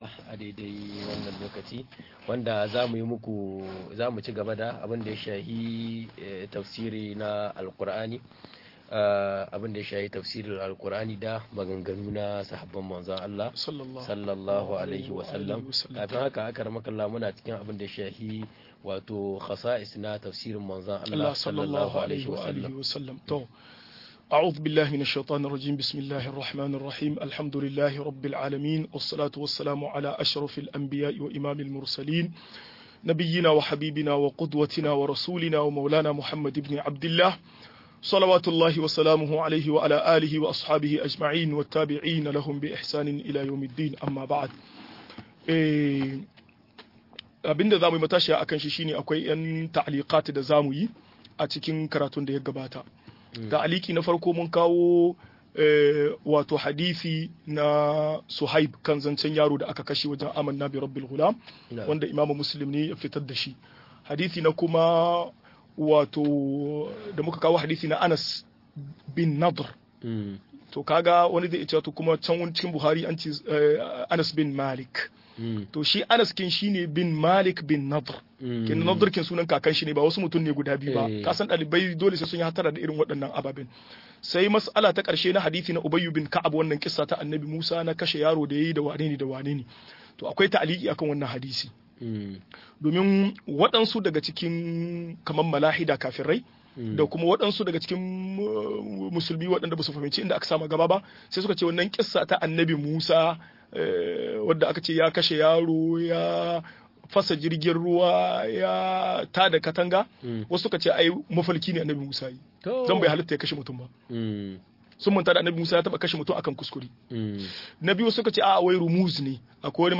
ka'idodin fassara Alkur'ani da maganar Sahabbai - MUHADARA